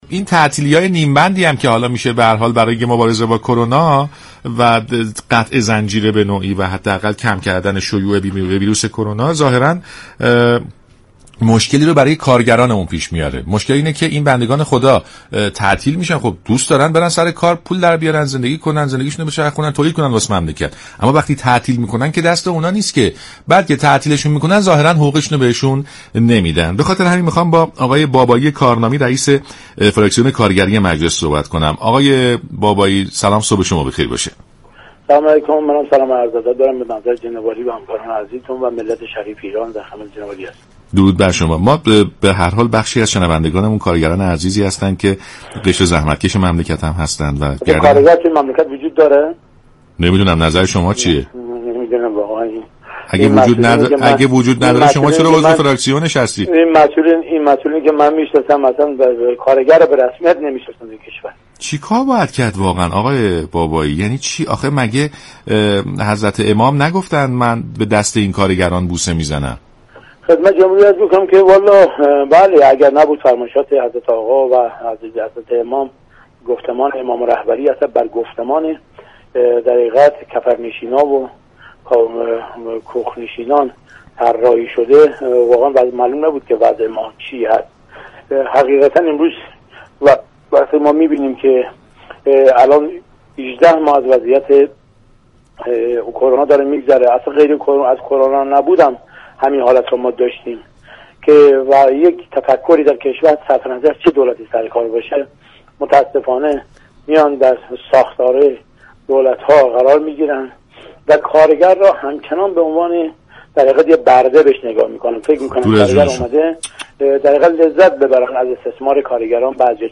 به گزارش شبكه رادیویی ایران، علی بابایی كارنامی رییس فراكسیون كارگری مجلس در برنامه «سلام صبح بخیر» به وضعیت كارگران پرداخت و گفت: تفكر شكل گرفته در ساختار دولت های ایران نگاه خوبی به كارگران ندارد و این در حالی است كه در بیشتر كشورهای دنیا 5 درصد از سود اقتصادی خود را از محل توجه به نیروی انسانی و قشر كارگران به دست می آورند.